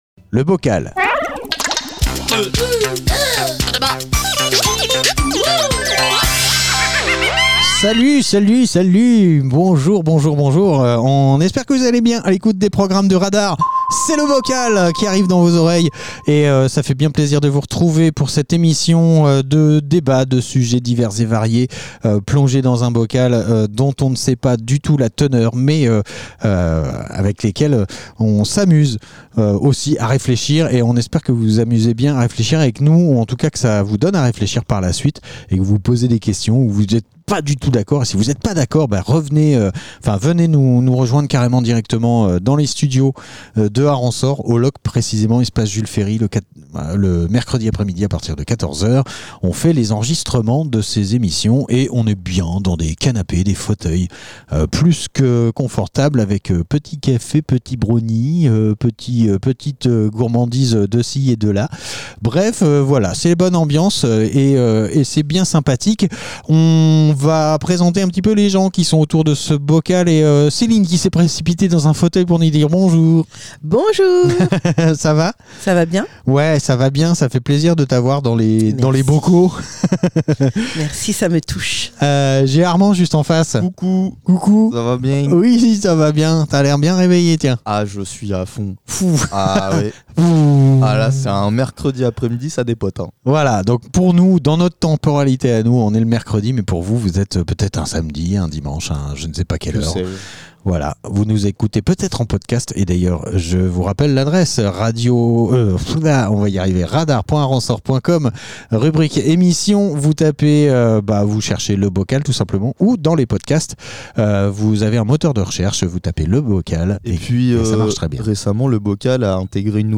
Une poignée d'invités propose des sujets de débats à bulletin secret. Ces petits papiers sont délicatement mélangés pour n'en tirer qu'un seul au sort. Le sujet, une fois dévoilé, donne lieu à des conversations parfois profondes, parfois légères, toujours dans la bonne humeur !